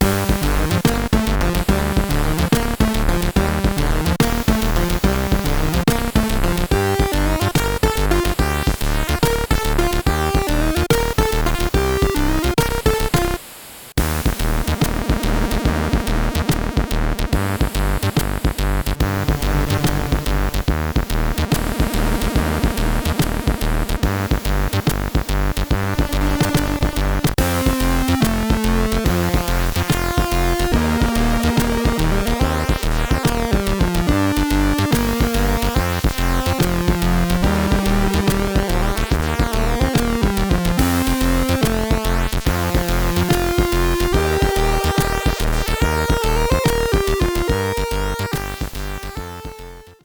How about some 8-bit chip-music?
a melancholic tune